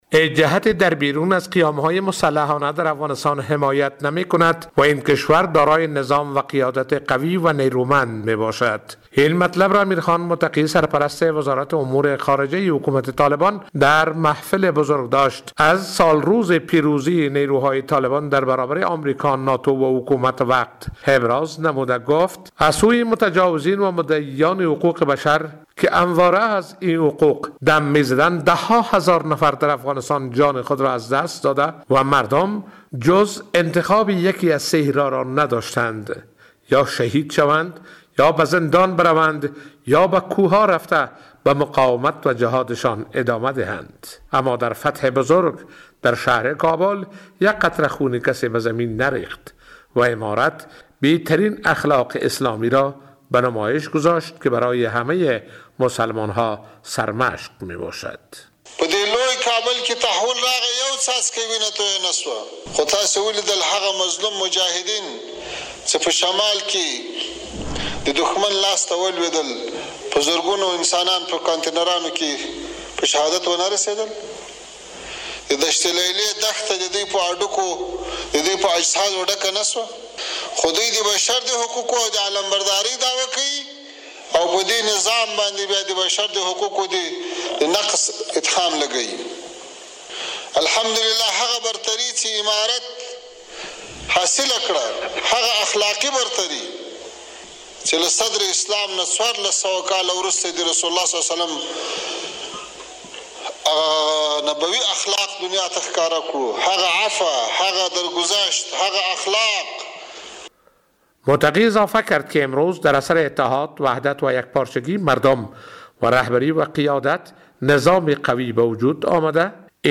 امیرخان متقی سرپرست وزارت امور خارجه حکومت طالبان در محفل بزرگداشت از سالروز پیروزی نیروهای طالبان در برابر امریکا، ناتو و حکومت وقت گفت: هیچ کسی از قیام ه...